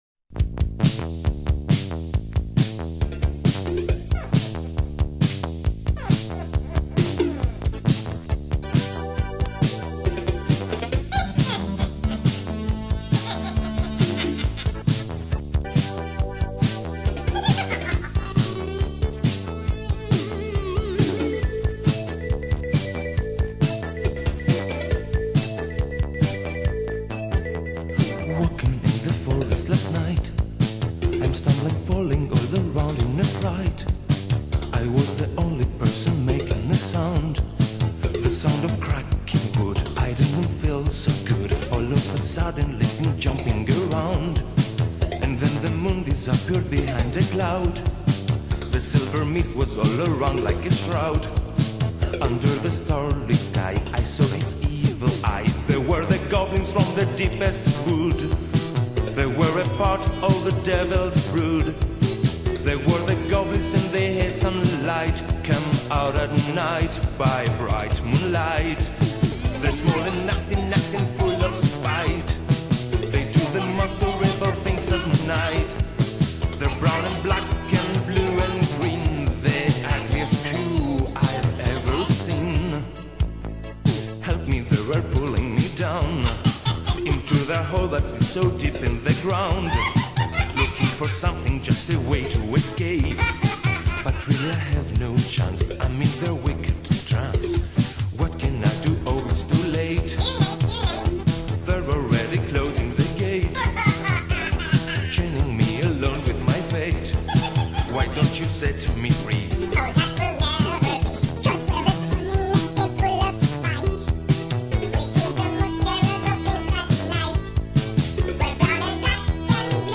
Je me suis perdu dans de la disco grecque.